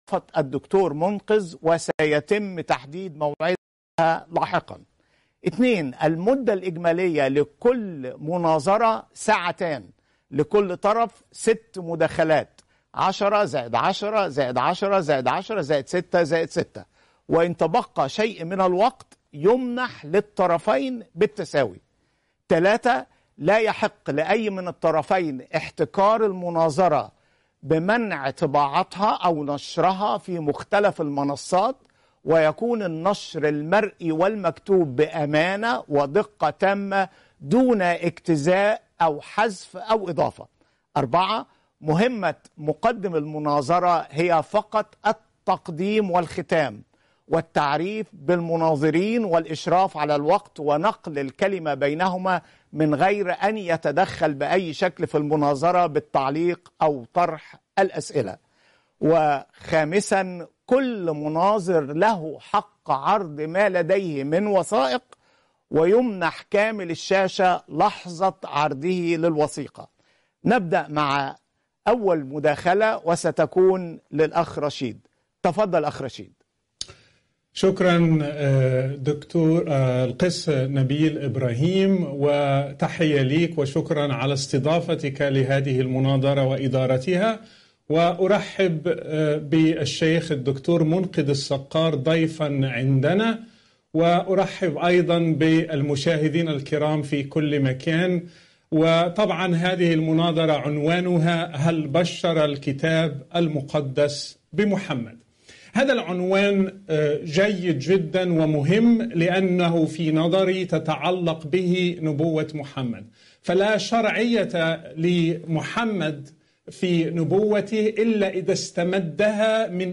المناظرة